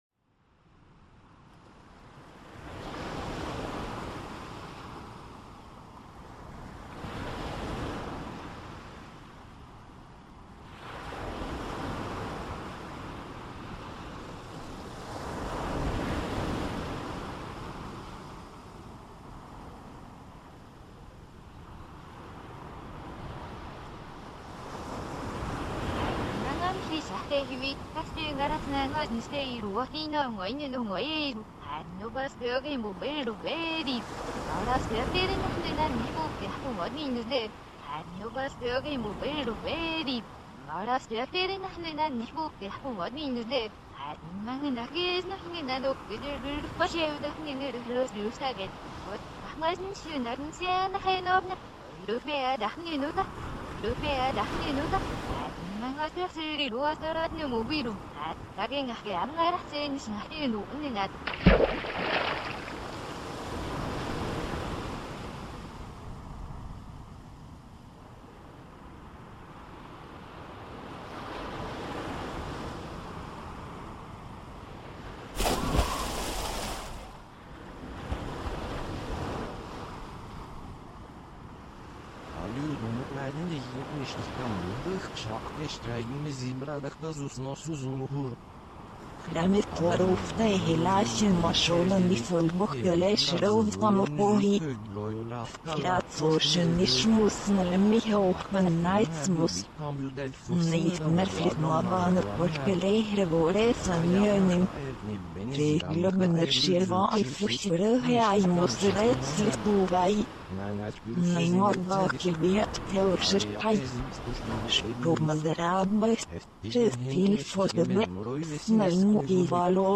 with different languages reciting the poem and overlapping water and ocean sounds.